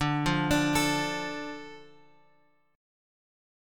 Dm chord {x 5 3 x 3 5} chord
D-Minor-D-x,5,3,x,3,5-8.m4a